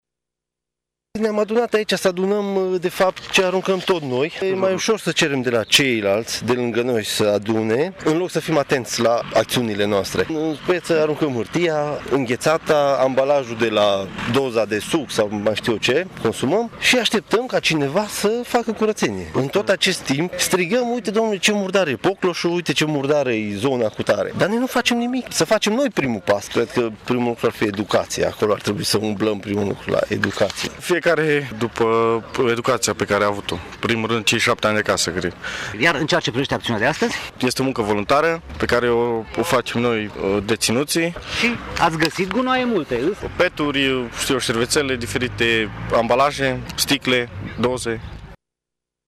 Cei doi deținuți cu care am stat de vorbă sunt conștienți că nu este bine să aruncăm gunoaiele și să pretindem ca alții să strângă după noi.